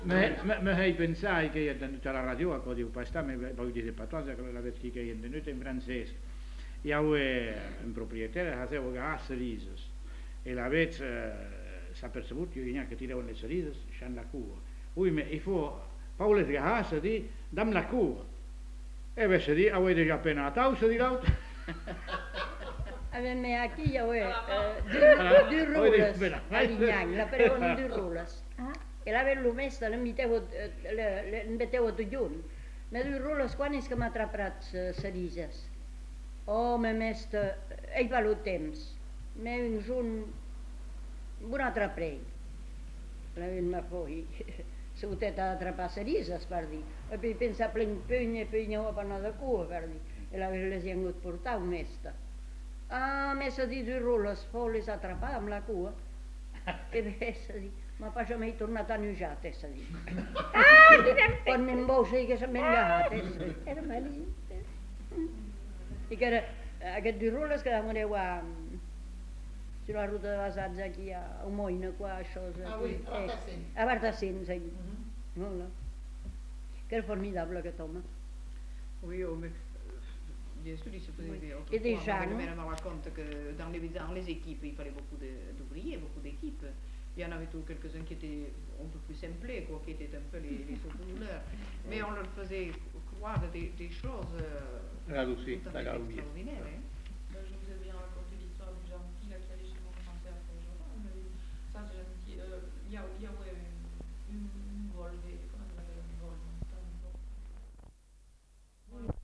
Lieu : Uzeste
Effectif : 2
Type de voix : voix d'homme ; voix de femme
Production du son : parlé
Classification : récit anecdotique